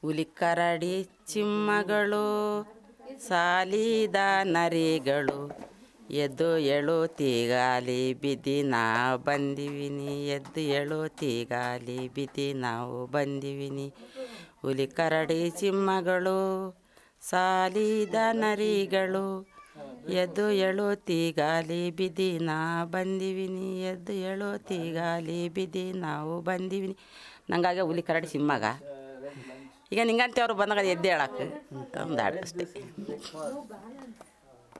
Performance of a folk song narrating the folk life of the Jenukuruba tribe
NotesThis is a performance of a folk song narrating the folk life of the Jenukuruba tribe in the forests of Karnataka.